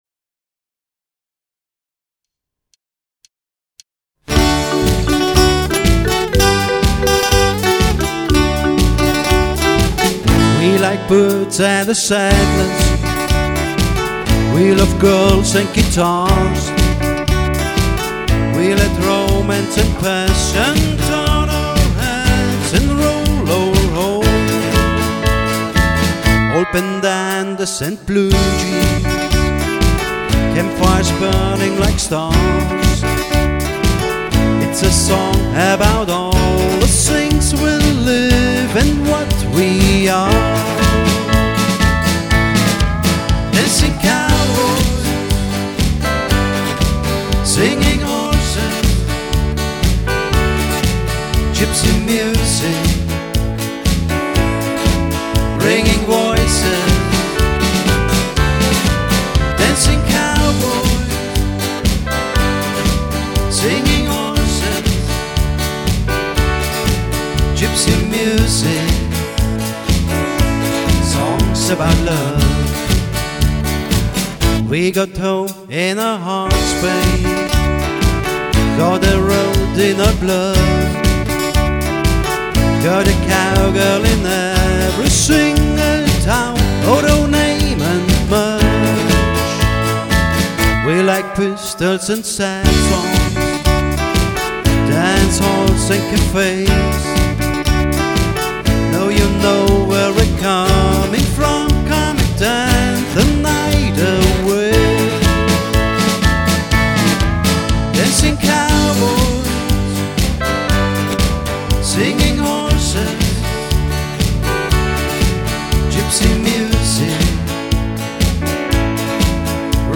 • Coverband
• Allround Partyband